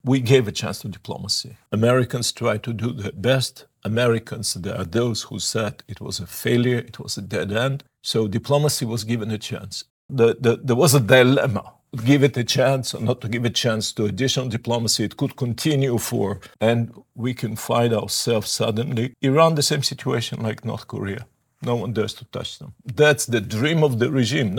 ZAGREB - Dok se cijeli svijet pita koliko će trajati rat na Bliskom istoku i kakve će globalne posljedice ostaviti, odgovore na ta pitanja potražili smo u Intervjuu tjedna Media servisa od izraelskog veleposlanika u Zagrebu Garyja Korena.